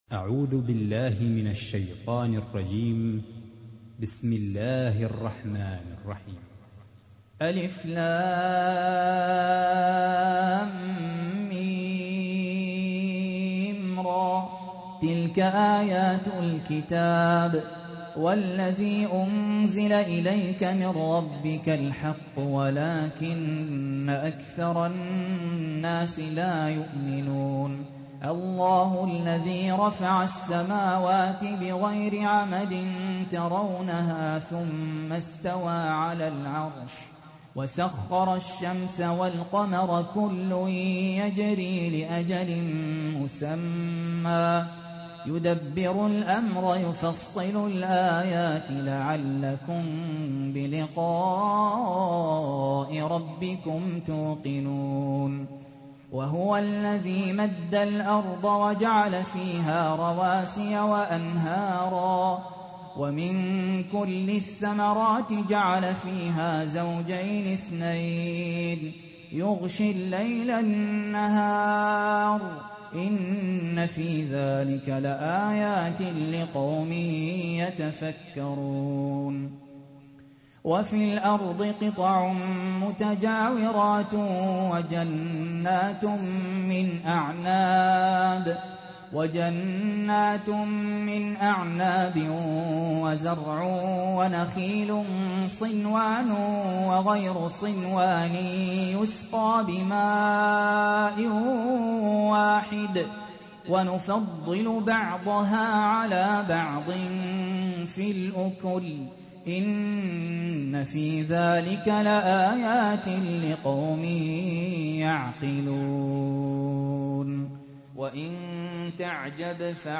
تحميل : 13. سورة الرعد / القارئ سهل ياسين / القرآن الكريم / موقع يا حسين